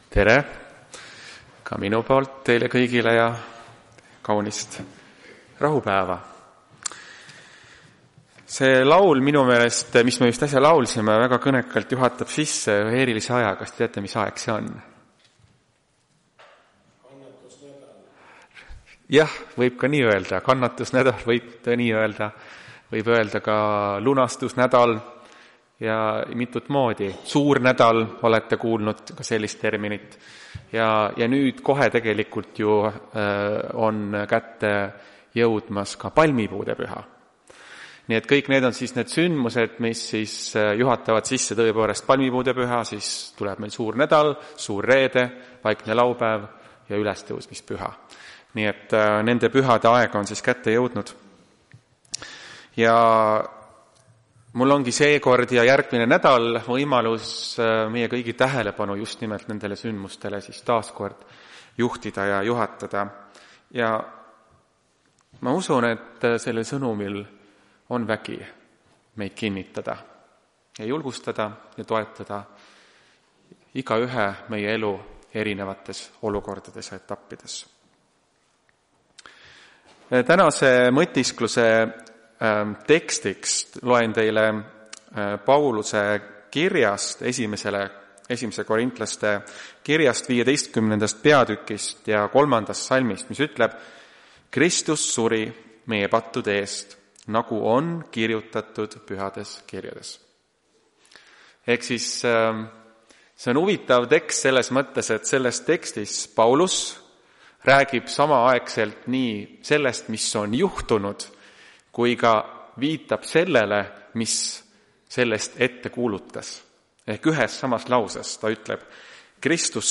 Tartu adventkoguduse 28.03.2026 teenistuse jutluse helisavestis.
Jutlused